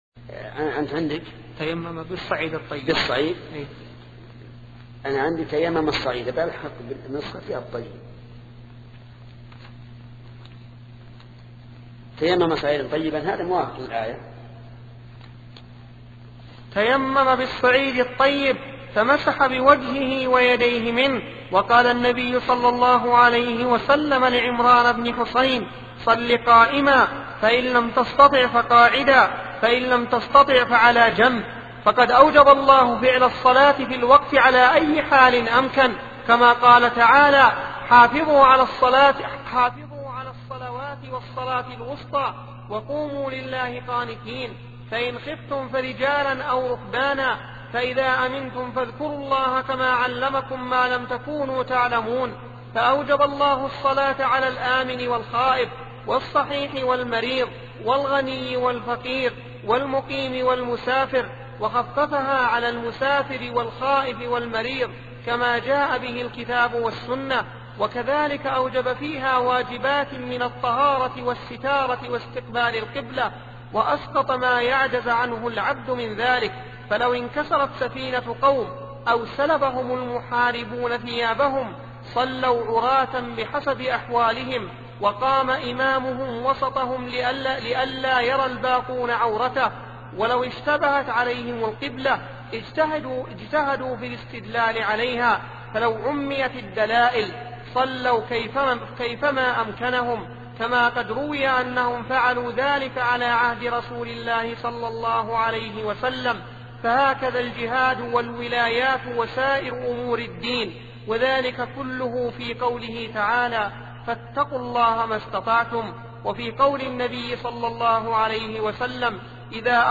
سلسلة مجموعة محاضرات التعليق على السياسة الشرعية لابن تيمية لشيخ محمد بن صالح العثيمين رحمة الله تعالى